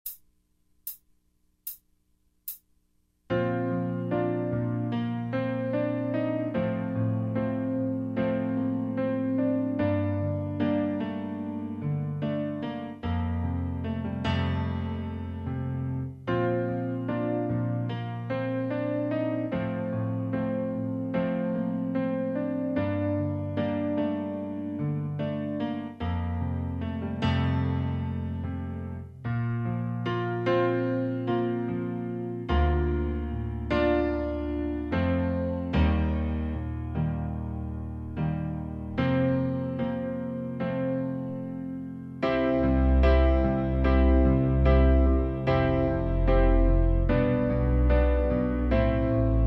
Key of B flat
Backing track only.